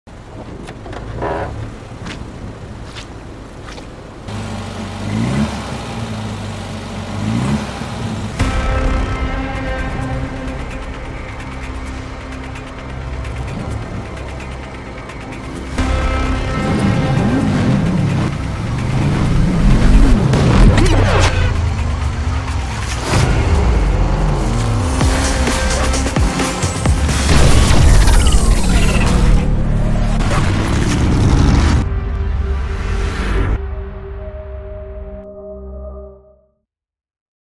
Mp3 Sound Effect